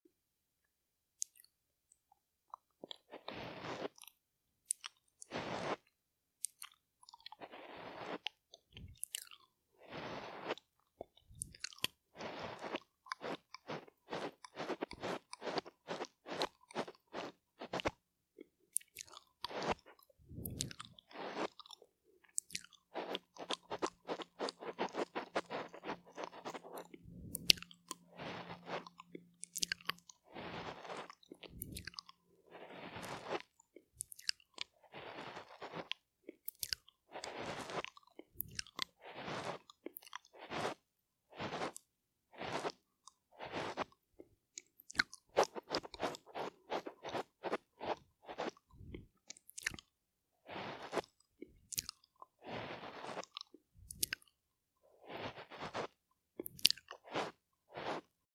Licking The Mic (Male Version) Sound Effects Free Download